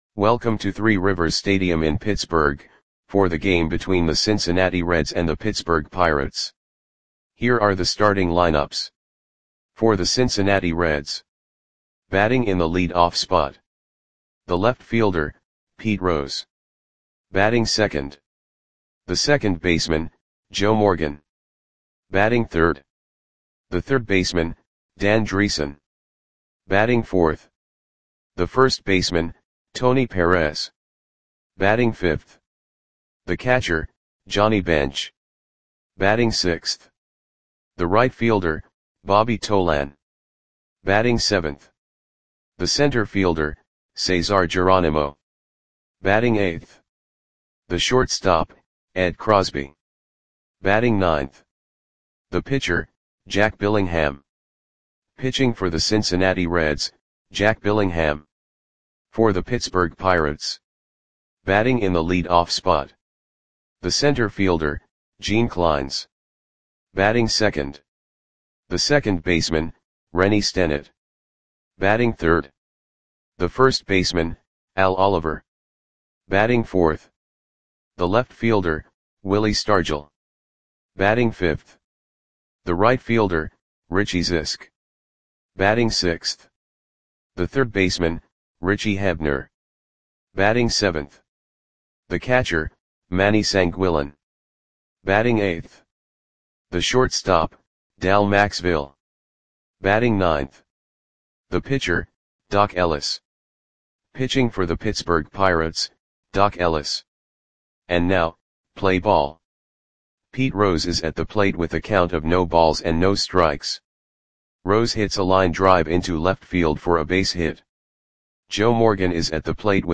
Audio Play-by-Play for Pittsburgh Pirates on August 15, 1973
Click the button below to listen to the audio play-by-play.